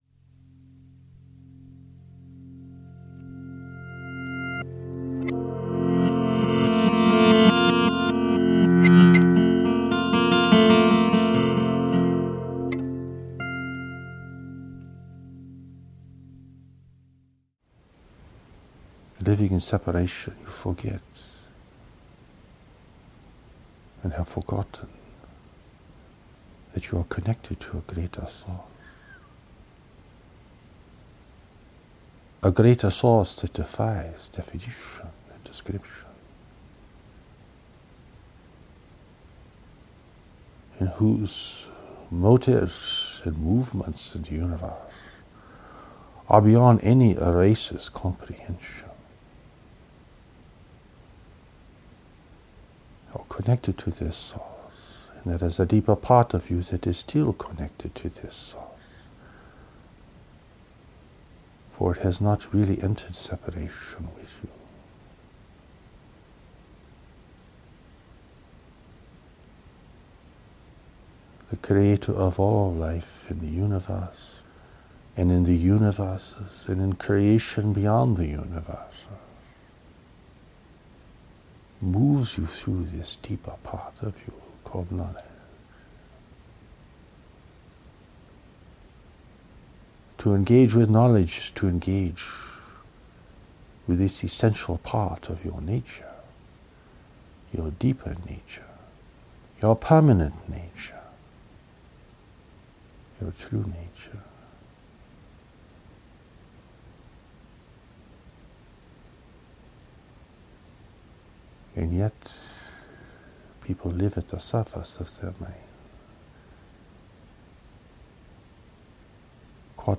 Hear the original spoken revelation: